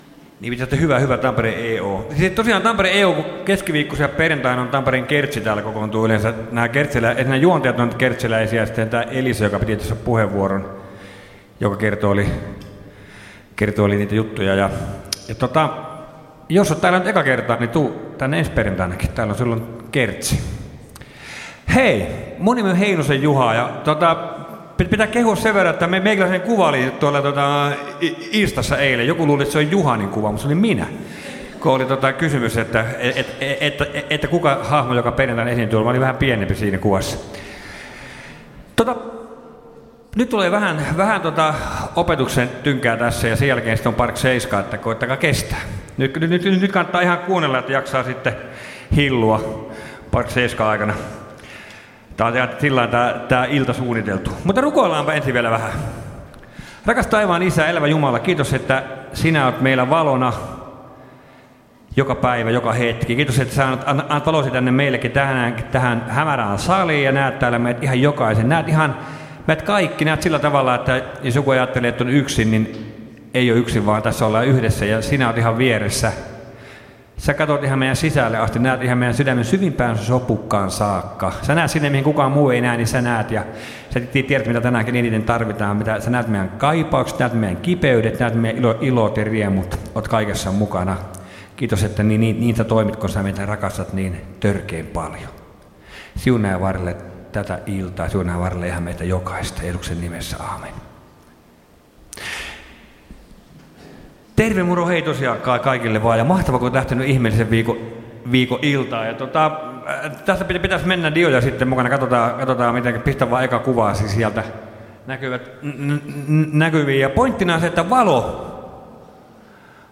Kokoelmat: Ihmeellinen viikko Tampereella 2018